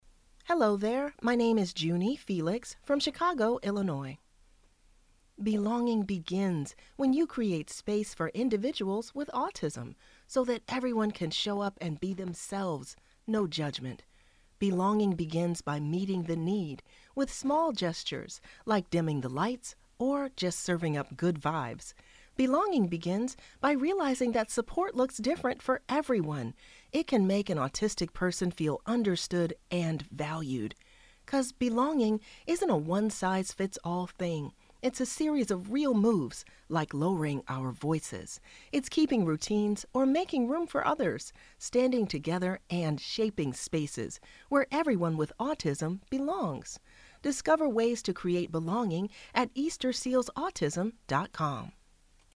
General American